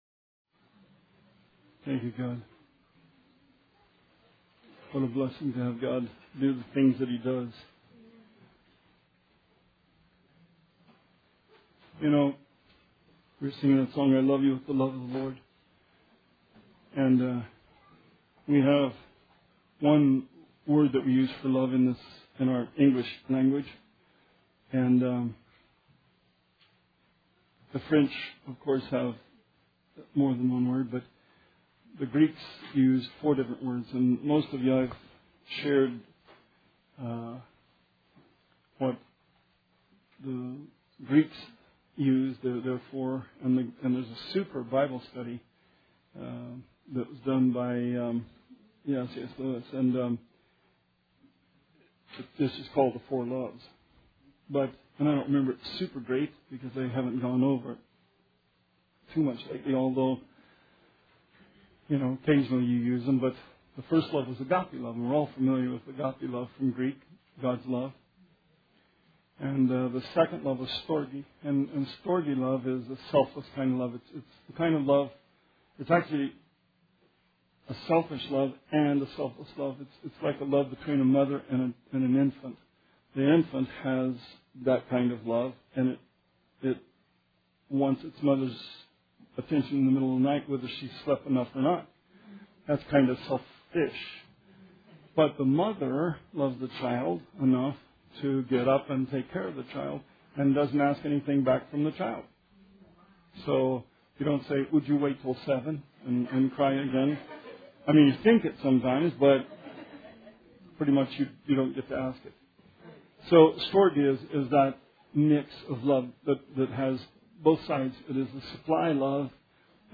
Bible Study 2/22/17